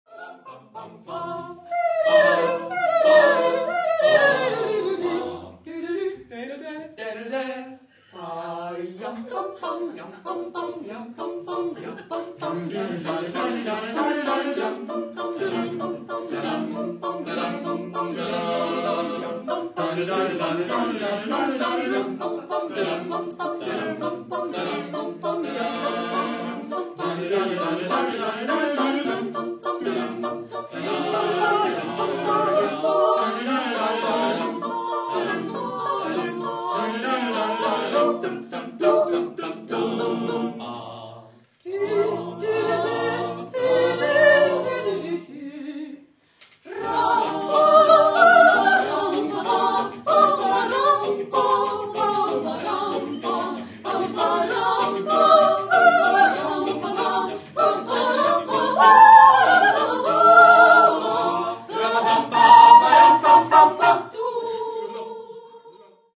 Junge Menschen singen von einer Zeit, von der sie keine Ahnung haben